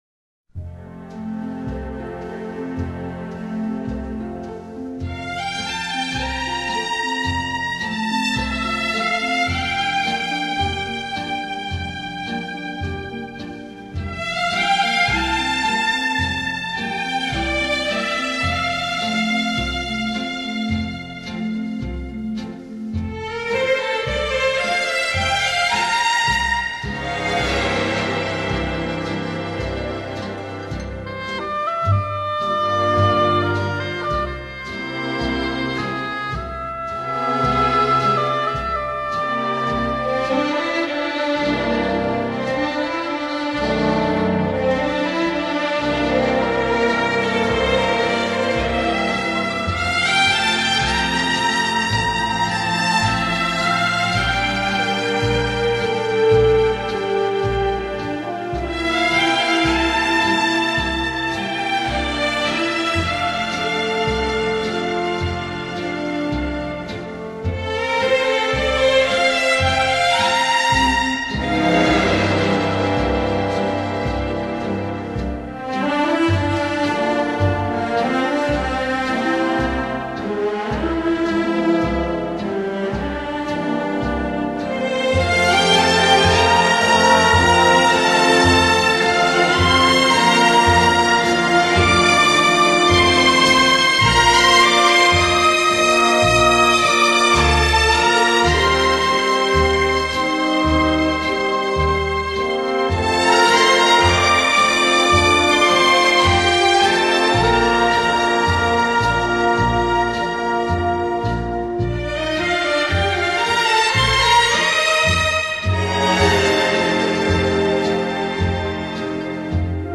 50、60年代风靡美国的轻音乐队。
乐队以两支低音萨克管作为主奏乐器，在配器上还加入了吉他，钢琴和低音鼓甚